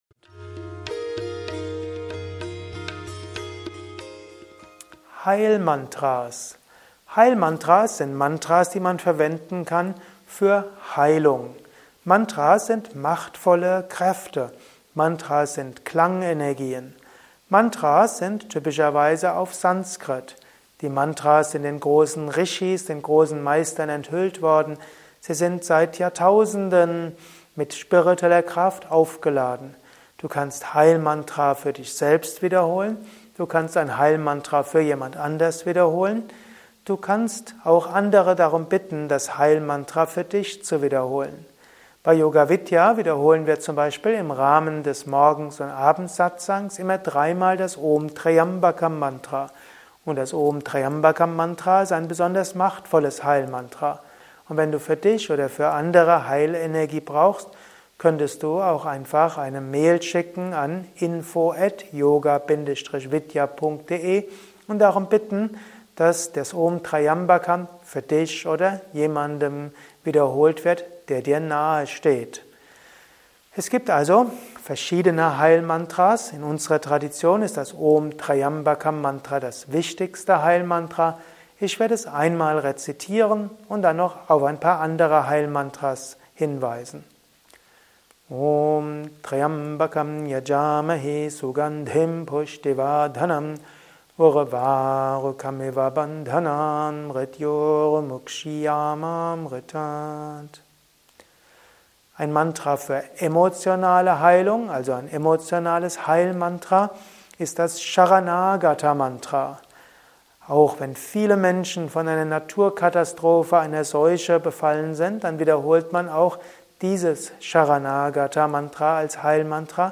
Er rezitiert einige dieser Heil-Mantras.